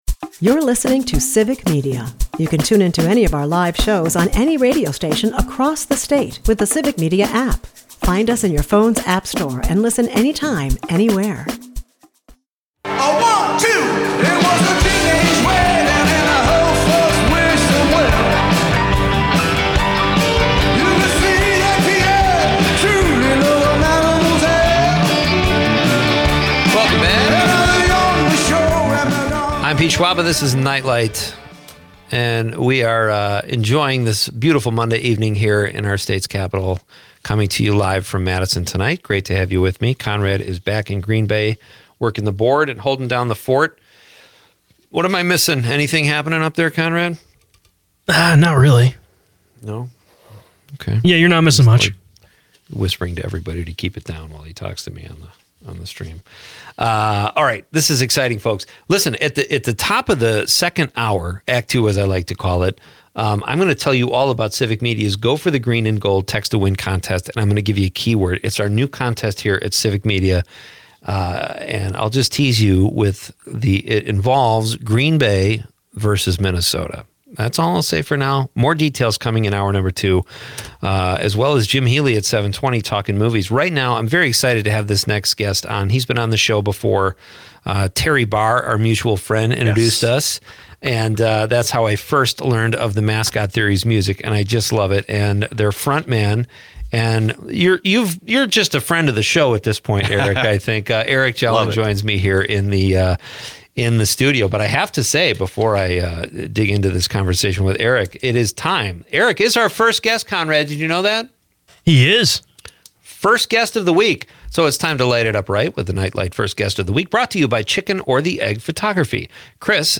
broadcasts live from Madison